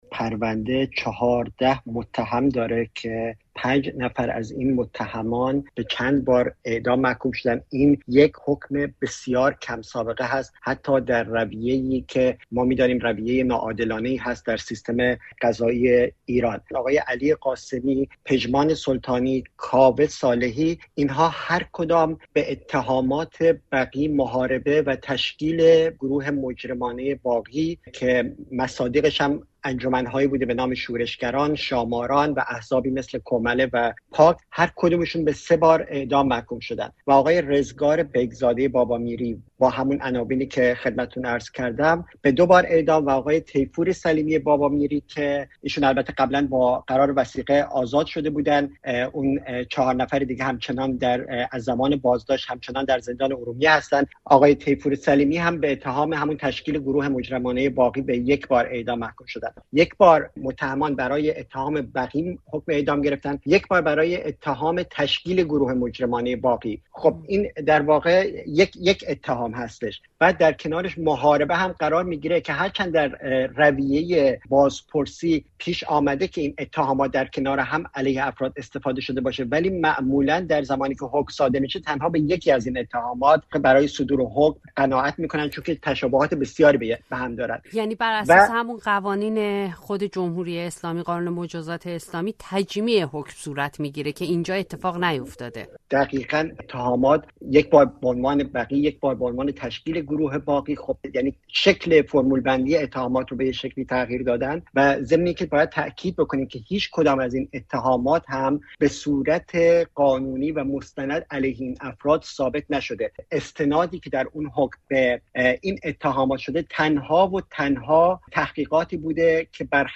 دربارهٔ این پرونده و احکام صادر شده گفت‌وگو کرده‌ایم.